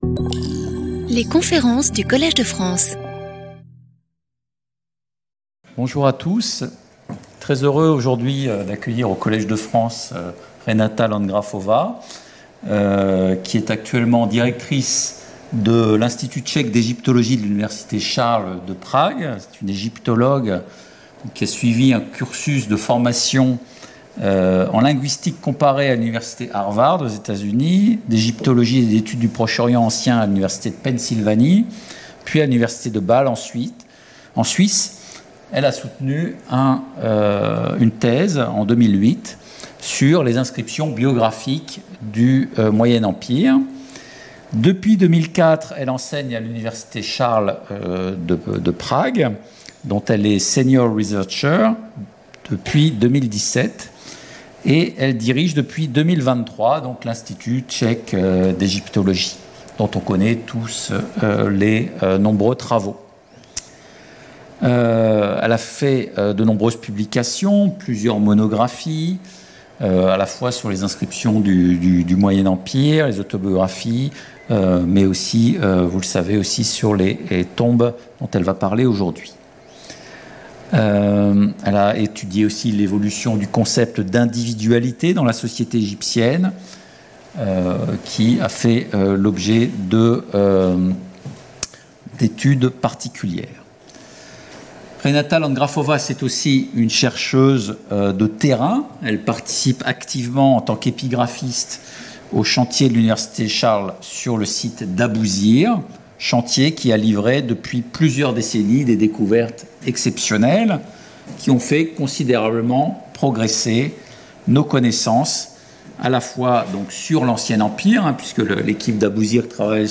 Lecture audio
Sauter le player vidéo Youtube Écouter l'audio Télécharger l'audio Lecture audio Conférence destinée au grand public.